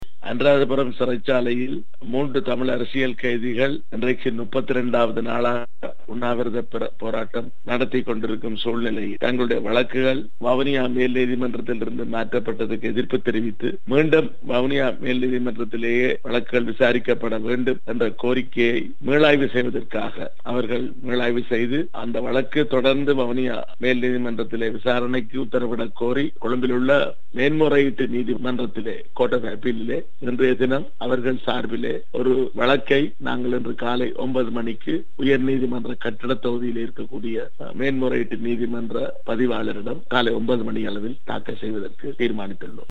அனுராதபுரம் சிறையில் உணவுத் தவிர்ப்பில் ஈடுபட்டுள்ள 3 அரசியல் கைதிகள் தொடர்பான மேன்முறையீடு இன்று மேற்கொள்ளப்படவுள்ளதாக தெரிவிக்கப்பட்டுள்ளது. வடமாகாண சபை உறுப்பினர் எம்.கே.சிவாஜிலிங்கம் இதனைத் தெரிவித்துள்ளார். குரல் சிவாஜி